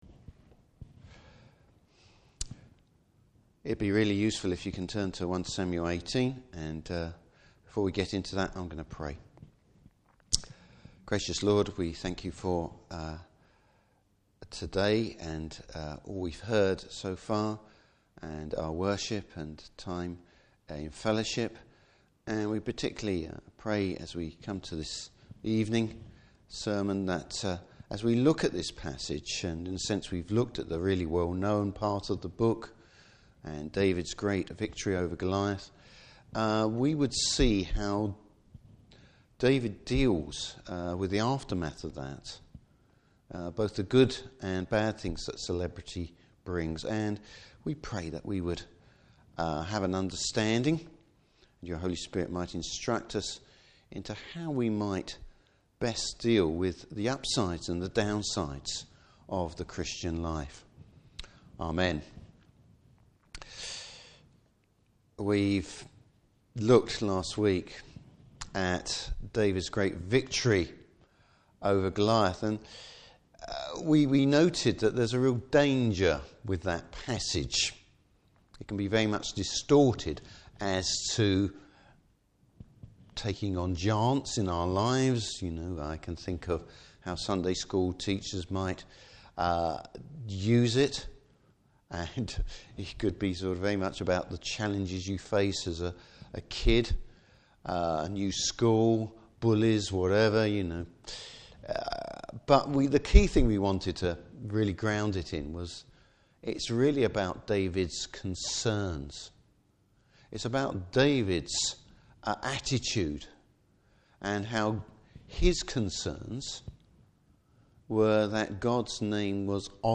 Service Type: Evening Service David’s servicing the Lord with humility in difficult circumstances.